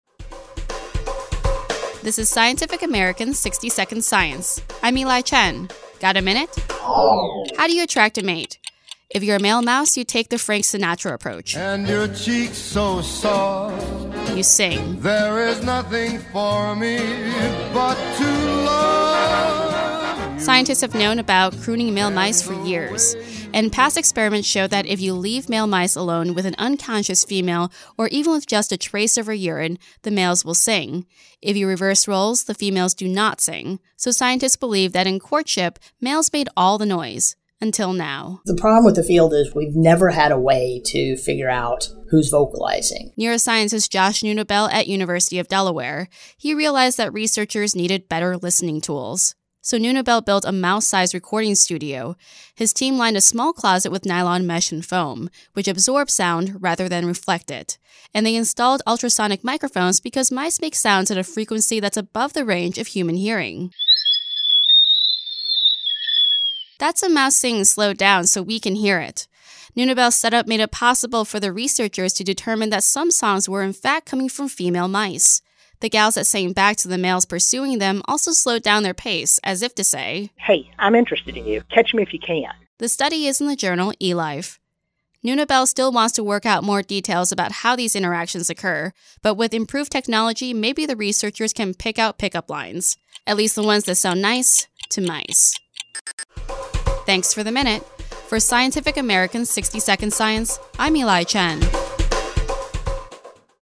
(The above text is a transcript of this podcast)